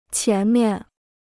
前面 (qián miàn) Free Chinese Dictionary